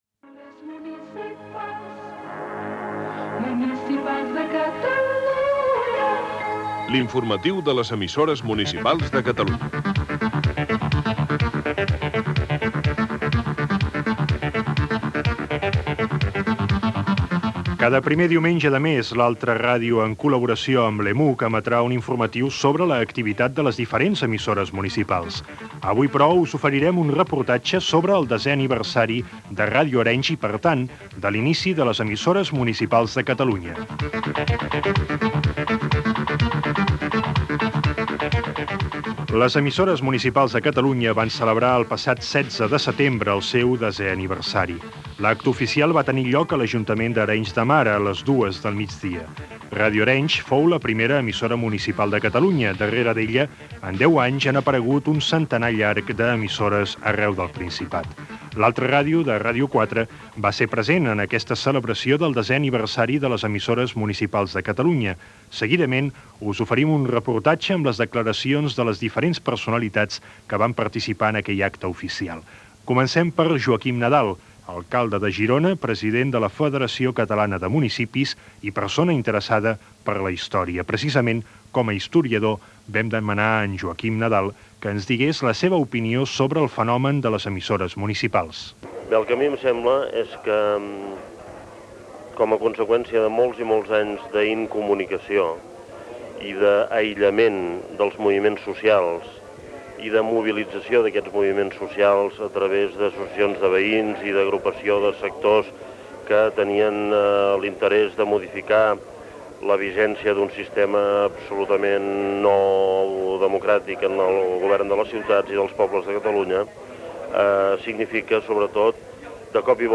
L'informatiu de les emissores municipals de Catalunya.
FM